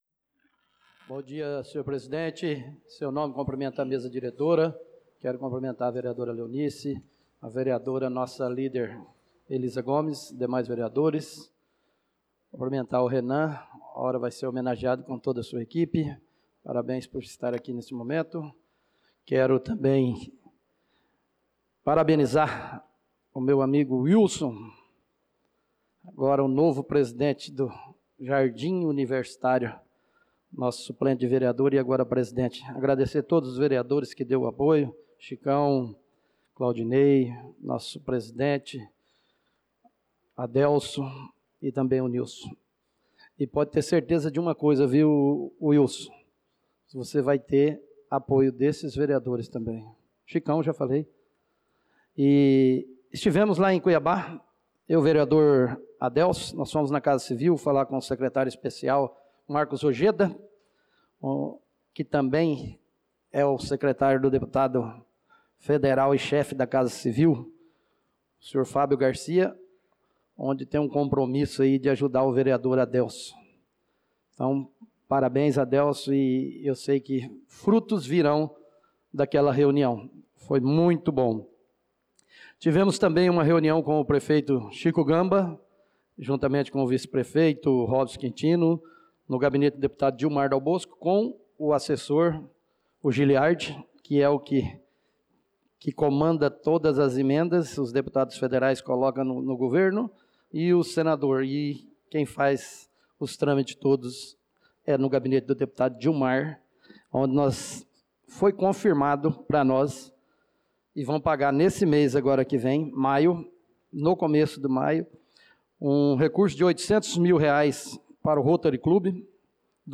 Pronunciamento do vereador Marcos Menin na Sessão Ordinária do dia 28/04/2025